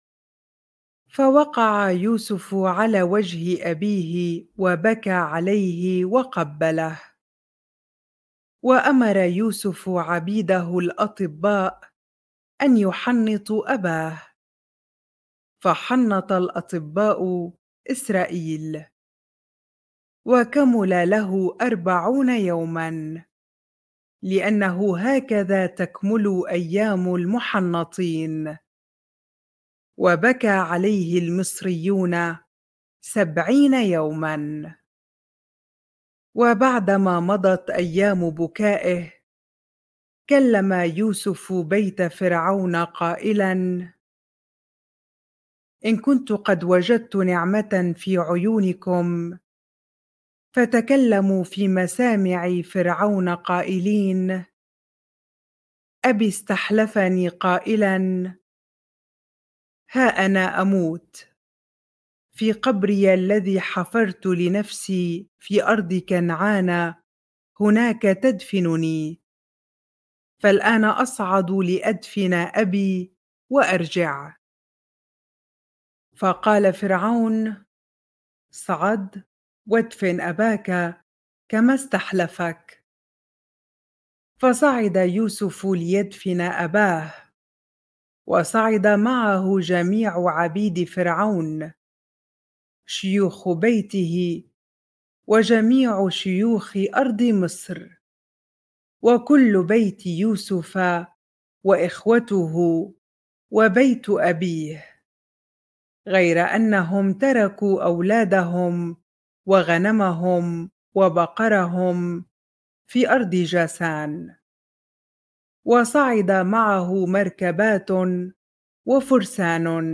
bible-reading-genesis 50 ar